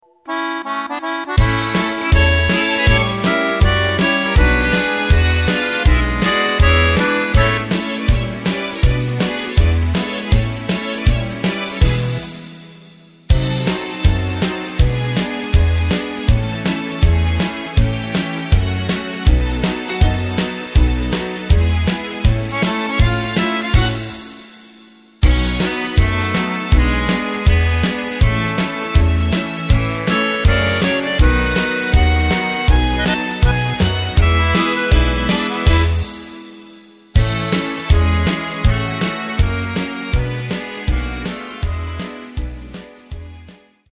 Takt:          4/4
Tempo:         161.00
Tonart:            C
Schlager aus dem Jahr 1949!
Playback mp3 Demo